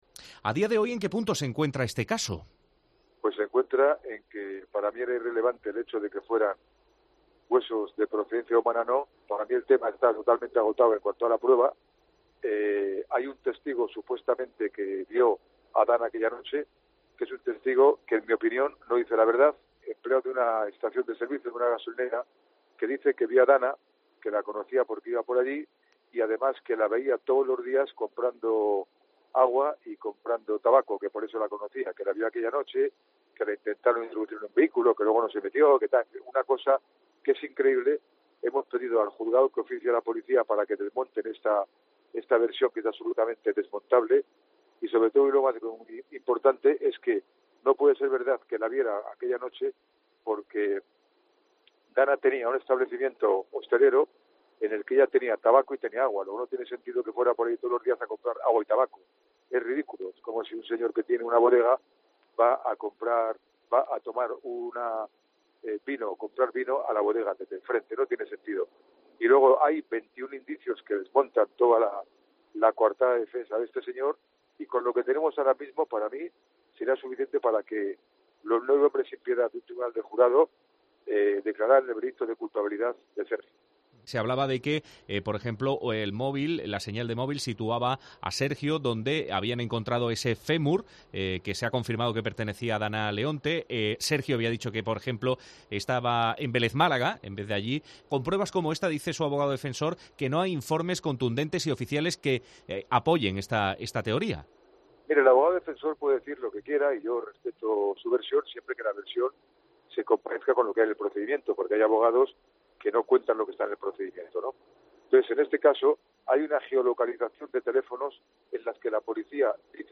El abogado Marcos García Montes en COPE MÁS Málaga